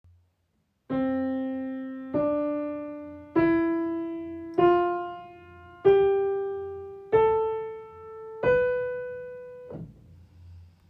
CDEFGAB sound